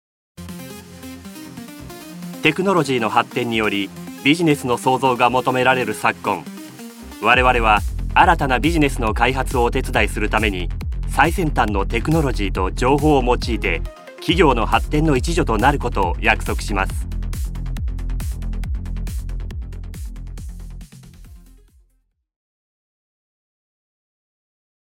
ボイスサンプル
企業VP